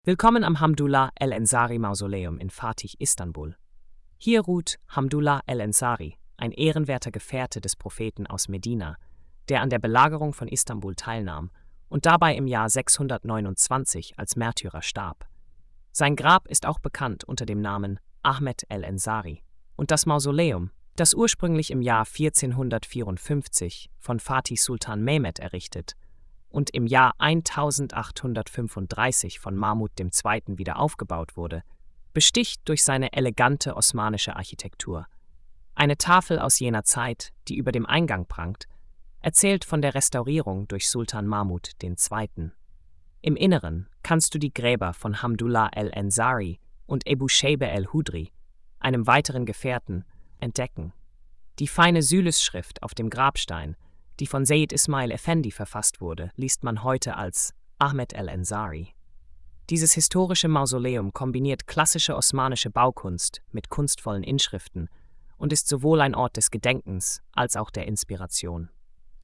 Audıo Erzählung: